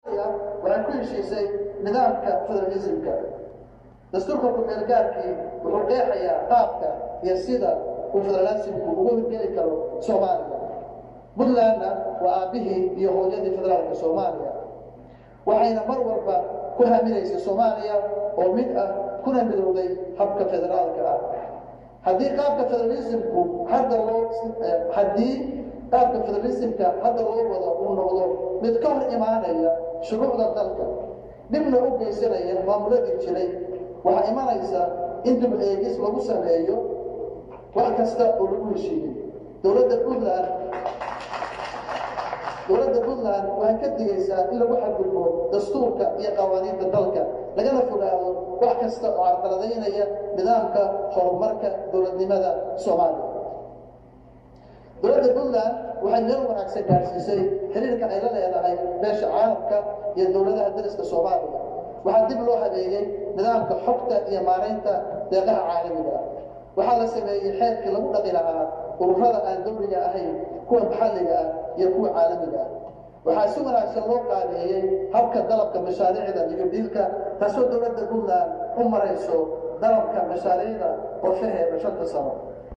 Garoowe–Madaxweynaha Puntland ayaa furey kalfadhiga barlamaanka Puntland iyadoo uu halkaasi ka jeediyay qudbad dheer. Cabdiwelli Gaas ayaa sheegay in shaqo uu qaban karo qof Somali ah aan la keeni Karin qof ajaanib ah. Waxana uu ka digey in hadii federal la qaadan waayo ay iyaguna dhinac kale eegi doonaan.